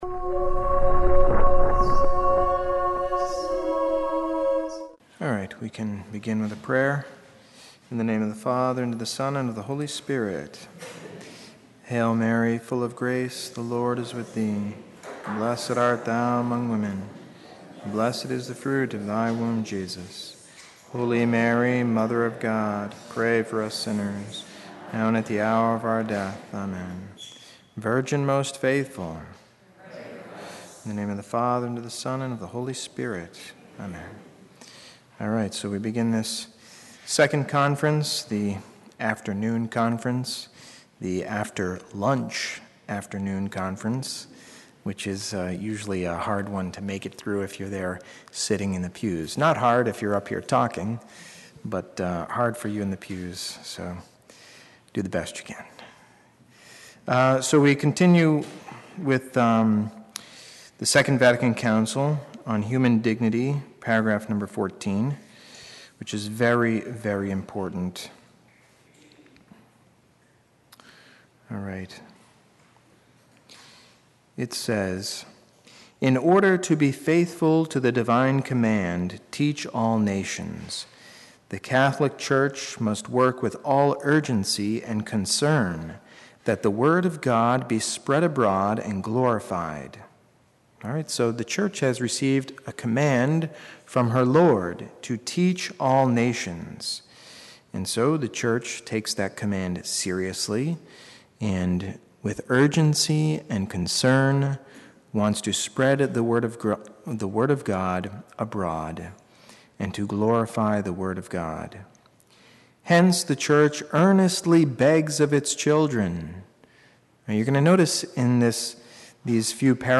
Conferences #211